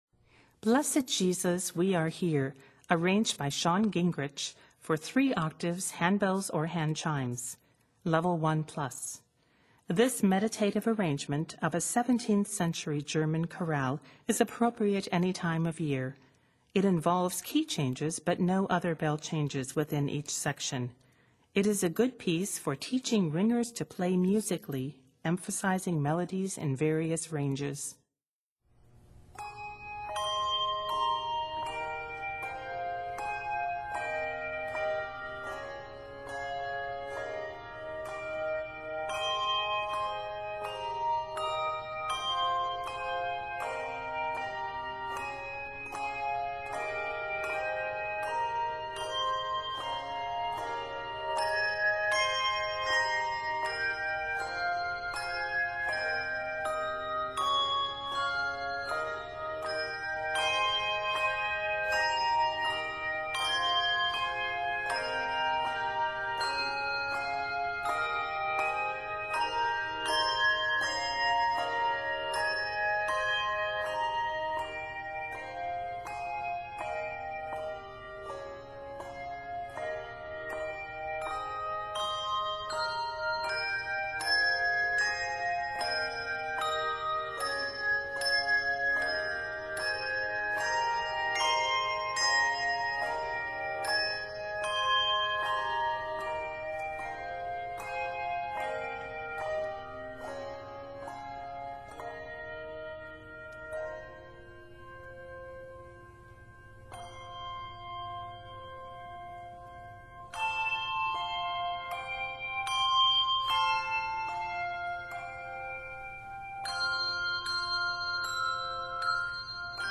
A total of 52 measures, it is set in Ab Major and F Major.